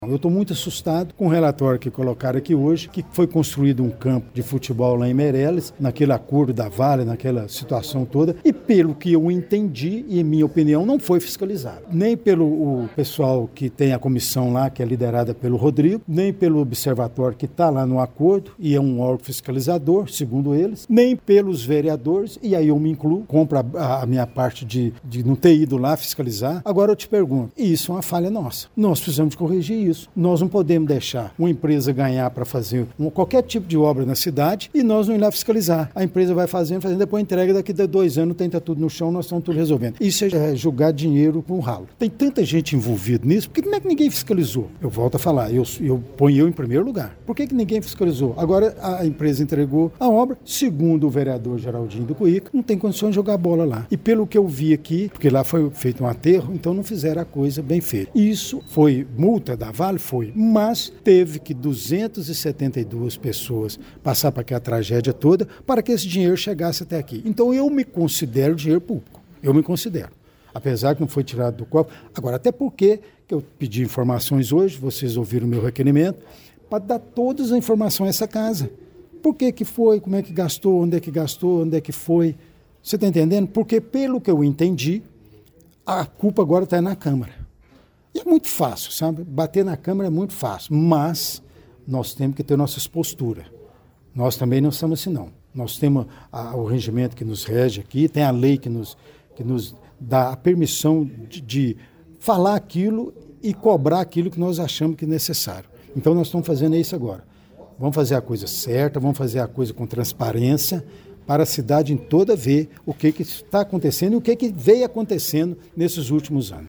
Durante a reunião ordinária da Câmara Municipal, realizada ontem, 2 de dezembro, o presidente da Mesa Diretora, Délio Alves Ferreira, fez um pronunciamento firme sobre a ausência de fiscalização nas obras executadas na comunidade rural de Meireles, custeadas com parte da multa paga pela mineradora Vale.